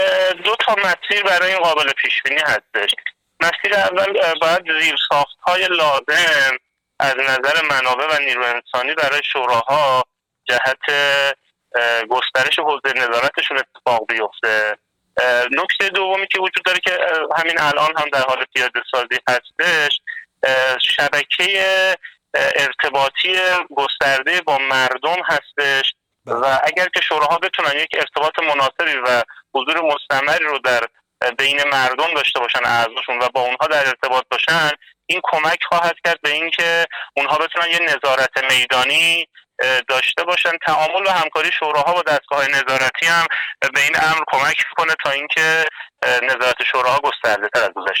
مهدی اقراریان در گفت‌وگو با ایکنا تبیین کرد؛